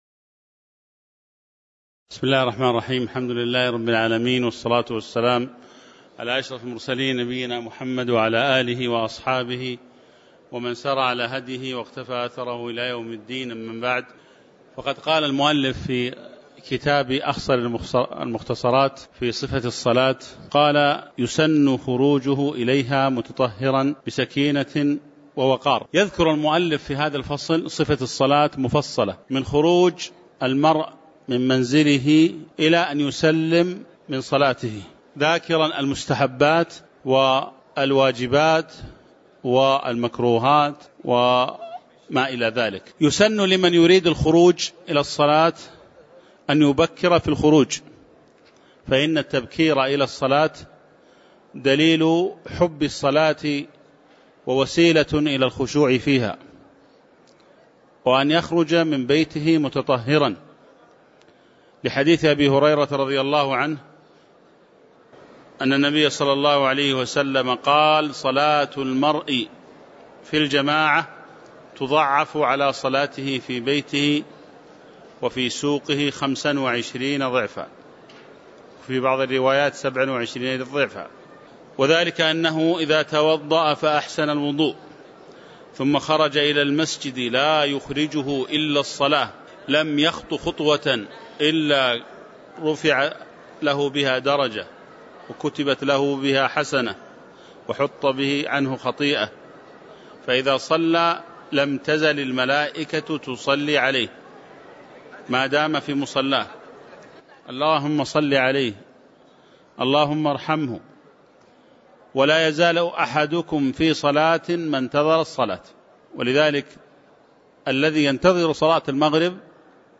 تاريخ النشر ٢١ رجب ١٤٤٠ هـ المكان: المسجد النبوي الشيخ